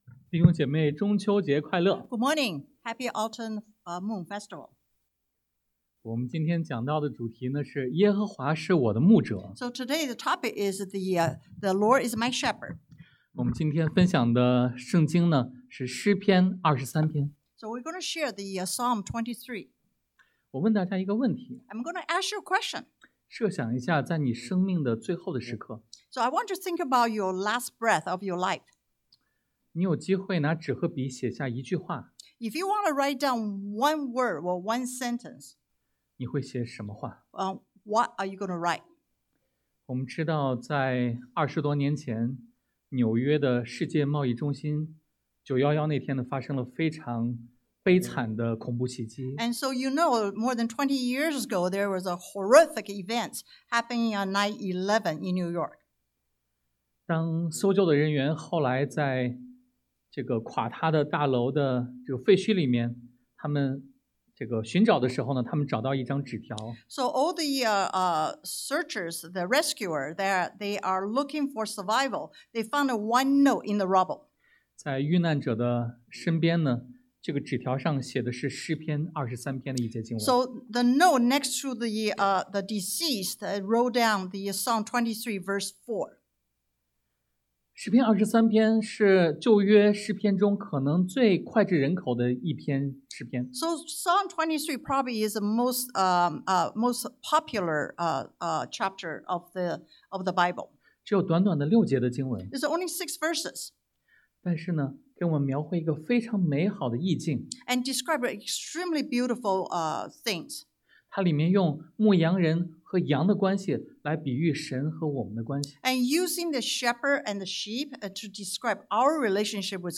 Service Type: Sunday AM 神的供应超乎想象 God’s Provision Beyond Imagination (1-3) 神的同在战胜恐惧 God’s Presence Conquers Fear (4) 神的恩典满溢人生 God’s Grace Overflows Life (5-6)